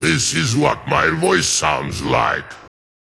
Text-to-Speech
sk_dragon.wav